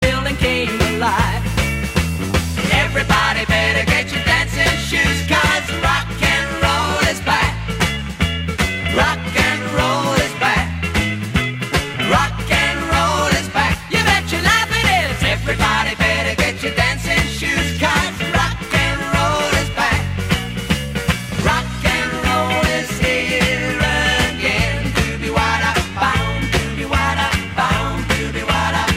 Besetzung: Blasorchester
Zwei bekannte Evergreens neu für Blasmusik bearbeitet.
E-Bass, Keyboard, Rhythmus-Gitarre u. Gesangstimme besetzt.